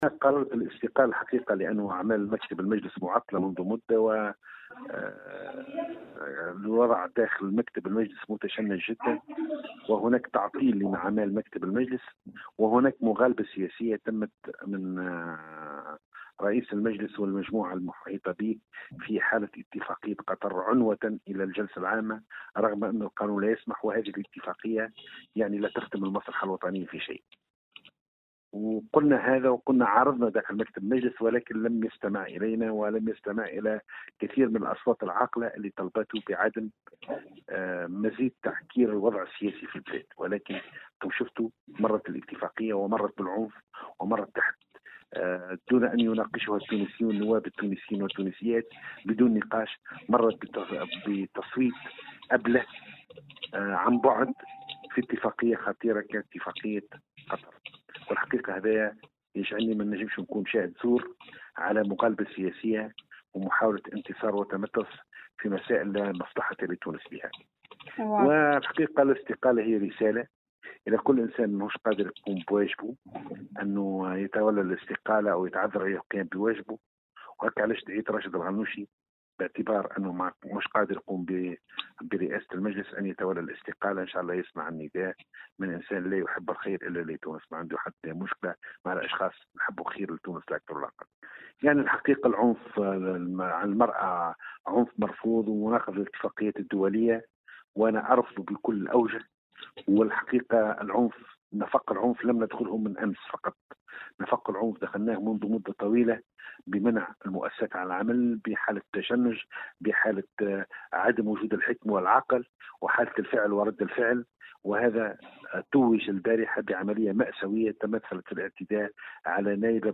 Dans une déclaration accordée aujourd’hui à Tunisie numérique, le député affilié au bloc National, Mabrouk Korchid a déclaré que sa démission est due au fait que le bureau de l’ARP ne fonctionnait plus correctement depuis un certain temps.